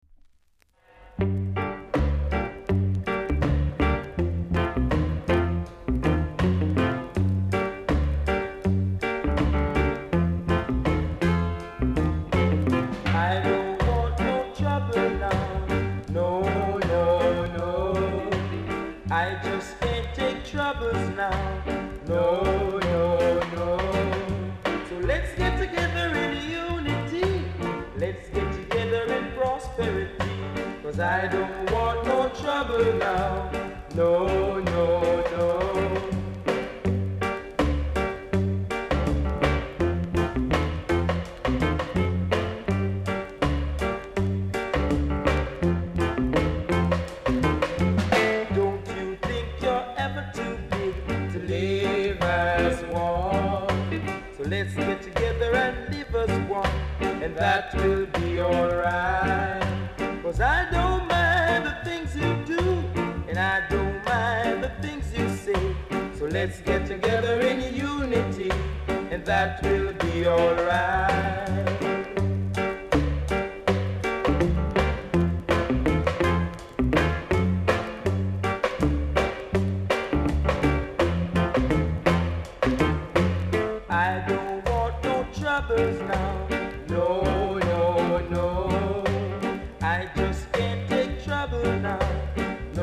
コメント 両面KILLER ROCKSTEADY!!VERY RARE!!※レーベル両面ともダメージあり